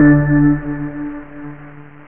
1 channel
bing.wav